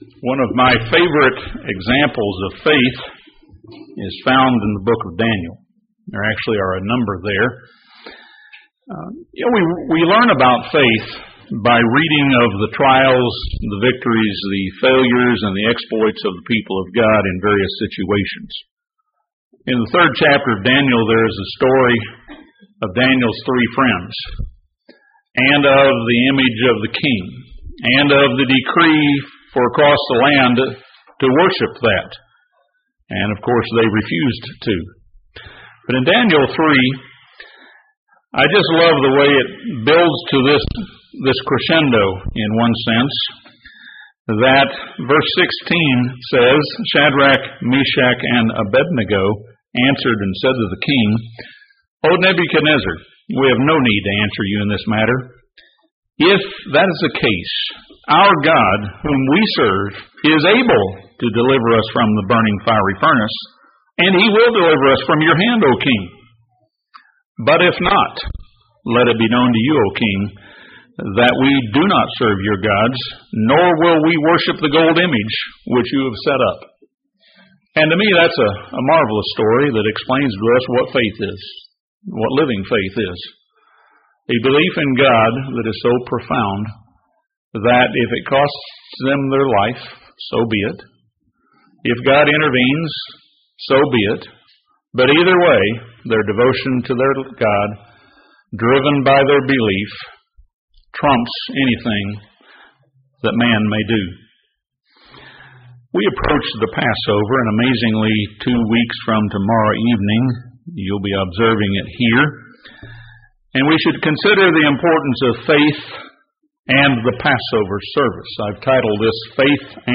This sermon considers why faith is necessary and offers steps we can take to increase our faith.